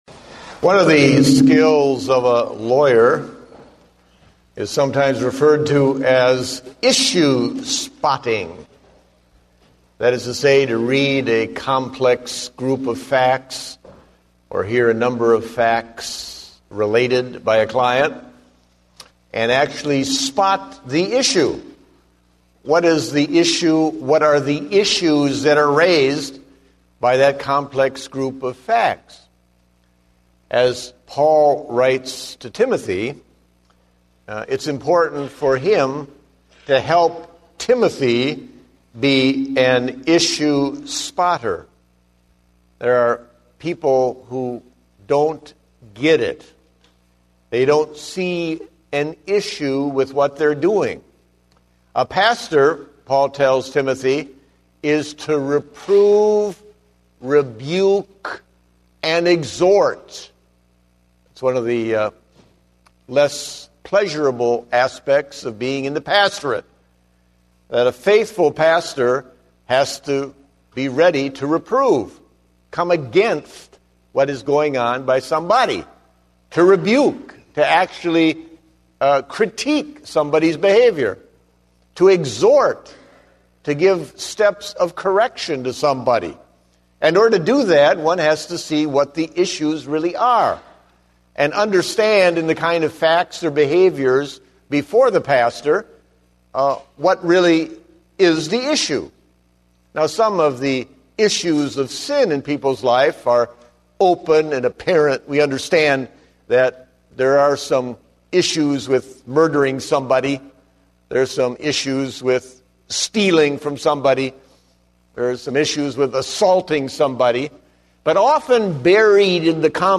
Date: October 31, 2010 (Morning Service)